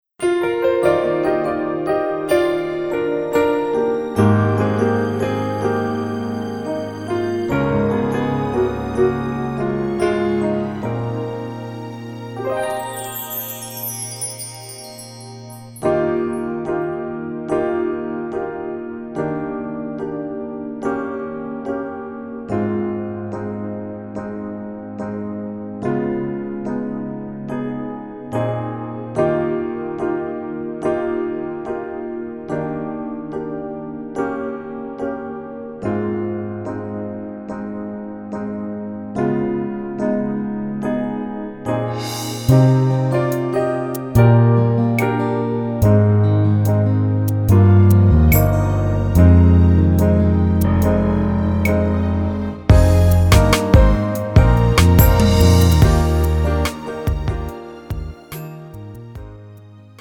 장르 가요 구분 Pro MR